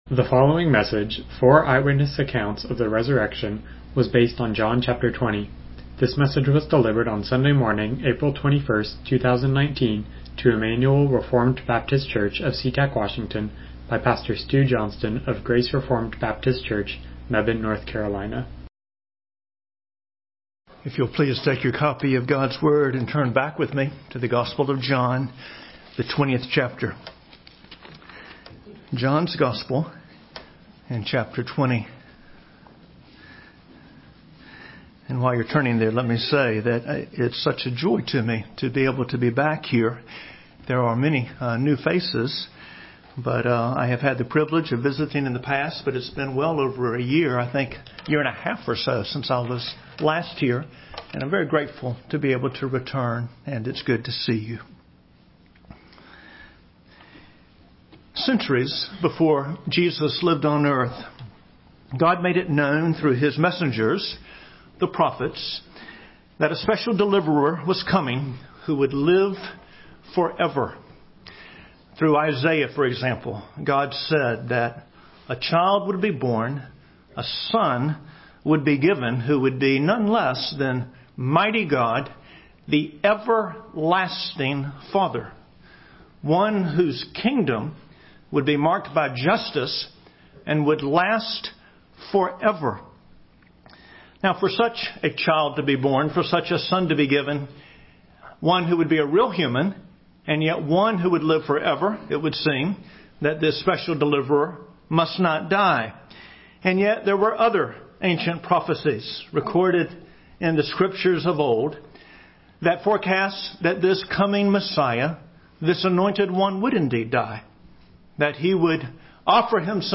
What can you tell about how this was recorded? John 20:1-31 Service Type: Morning Worship « Through Many Tribulations…